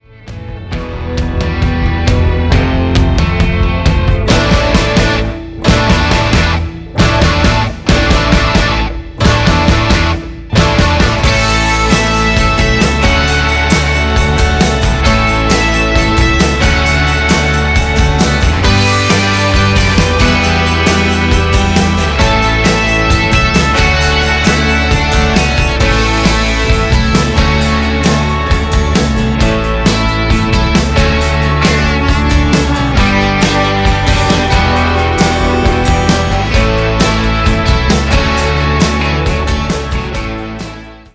instrumental mix